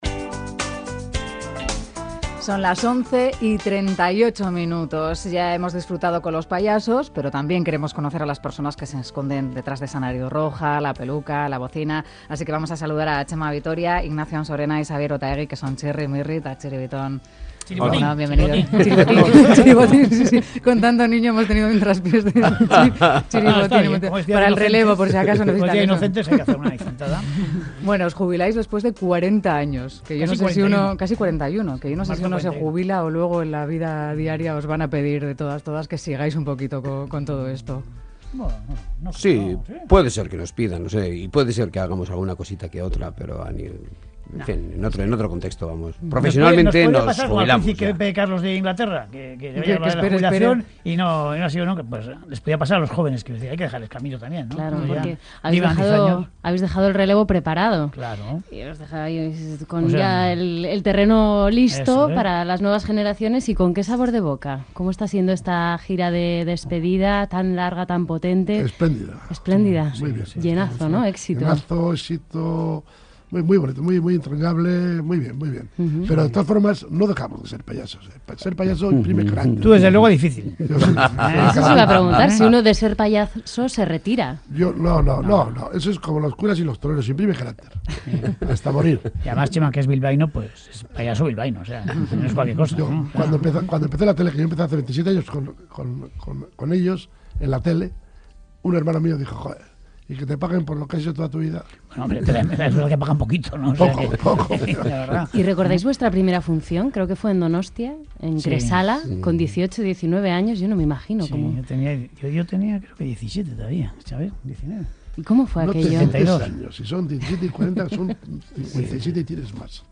Audio de la entrevista a Xabier Otaegi, Jose Ignazio Ansorena y Txema Vitoria, Txirri, Mirri eta Txiribiton | Boulevard
En ‘Boulevard’ hemos querido conocer a los tres míticos payasos: Txirri (Xabier Otaegi), Mirri (Jose Ignazio Ansorena) eta Txiribiton (Txema Vitoria)